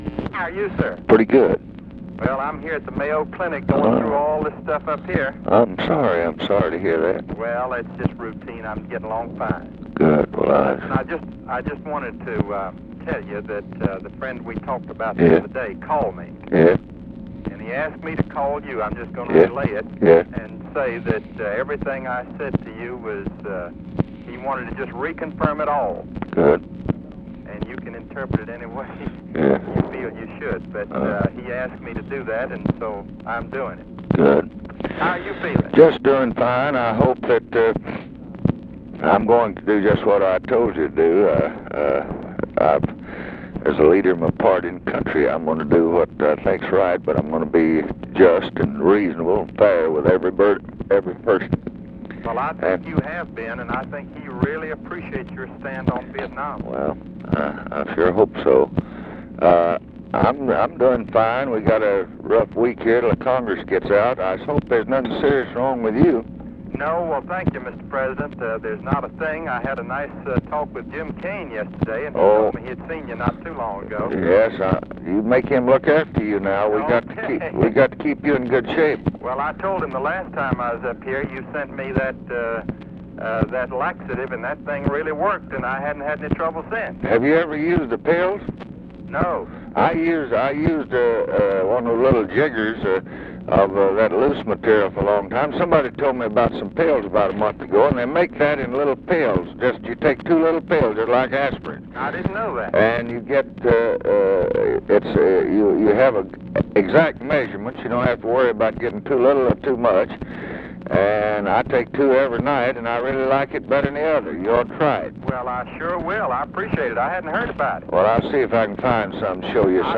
Conversation with BILLY GRAHAM, October 10, 1968
Secret White House Tapes